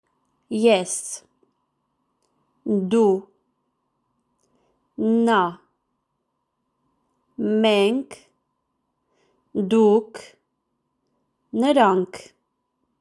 English Armenian (Eastern) Armenian (Western) Pronunciation (Eastern)